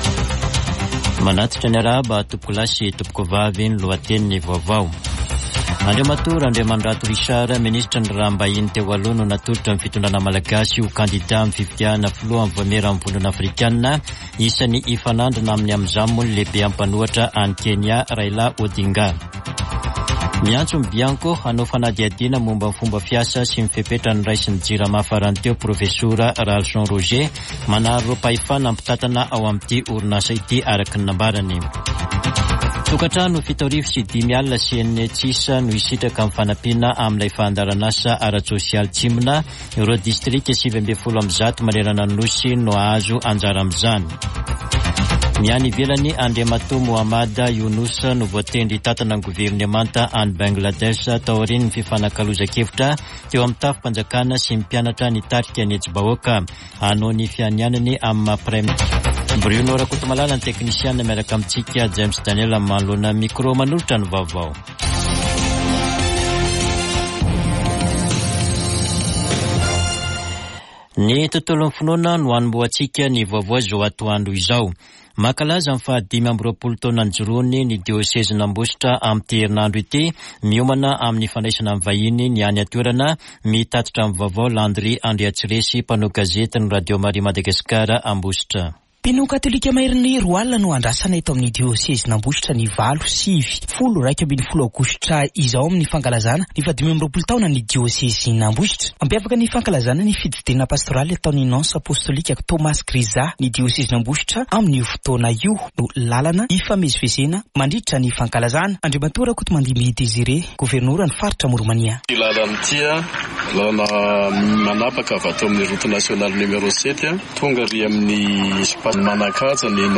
[Vaovao antoandro] Alakamisy 8 aogositra 2024